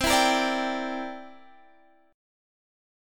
C9 chord